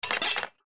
Metal Sound 2